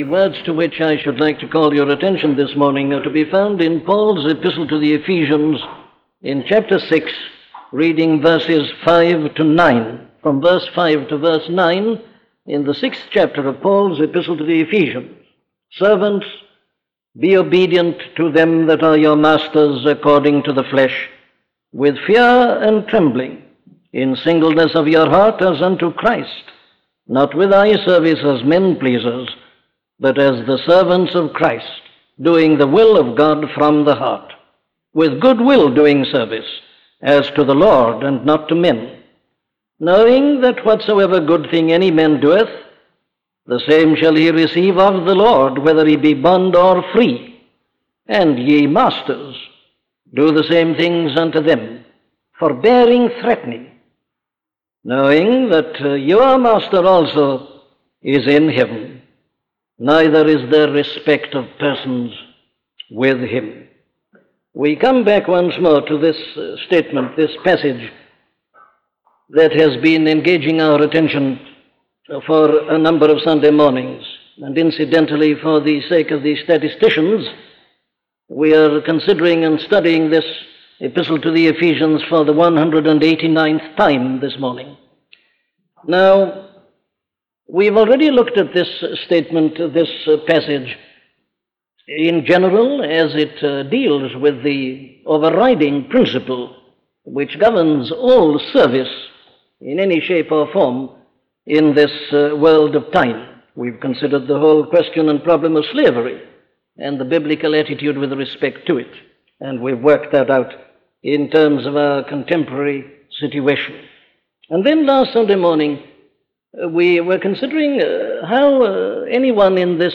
Dr Martyn Lloyd-Jones's major series of 232 sermons on Ephesians covering all 6 chapters of Paul's Epistle, plus a small collection of 5 other Ephesian sermons preached at Westminster Chapel.
This lengthy series is a systematic exposition of the epistle that was preached on Sunday mornings between 1954 and 1962, filled with thanksgiving and prayer as Paul intended.